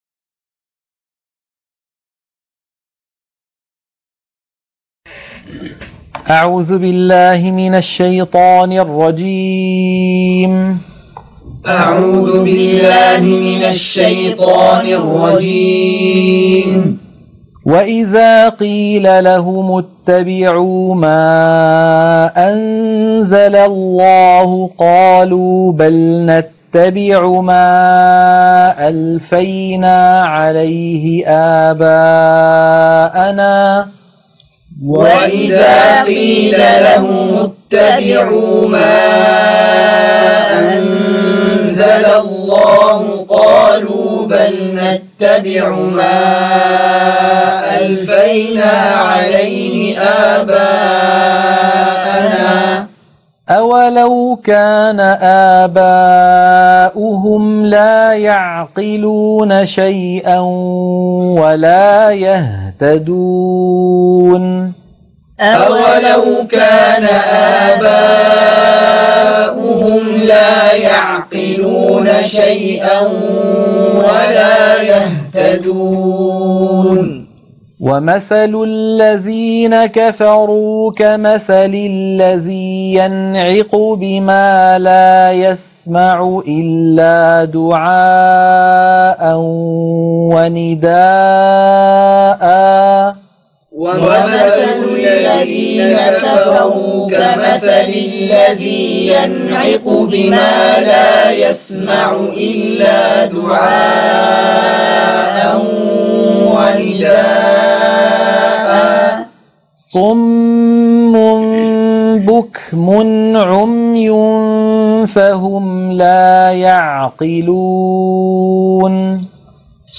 تلقين سورة البقرة - الصفحة 26 _ التلاوة المنهجية - الشيخ أيمن سويد